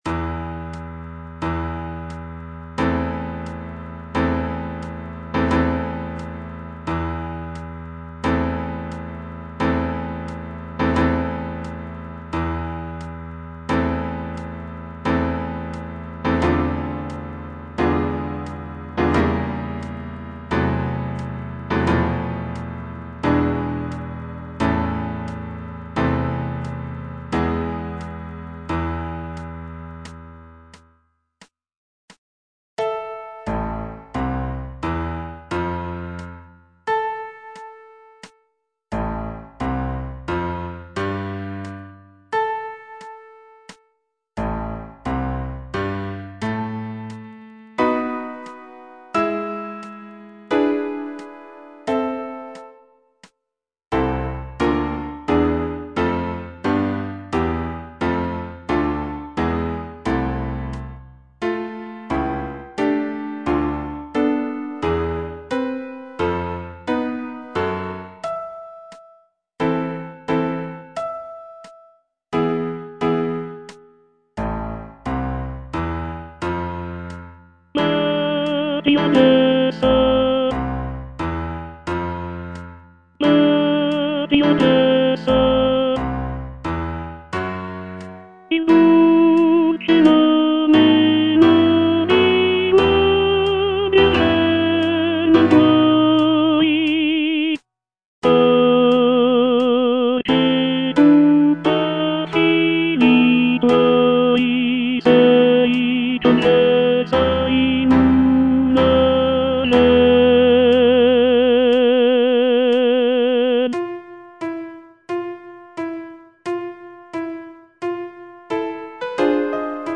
G. VERDI - CORO DI PROFUGHI SCOZZESI FROM "MACBETH" Tenor I (Voice with metronome) Ads stop: auto-stop
The piece features rich harmonies and powerful melodies that evoke a sense of sorrow and longing.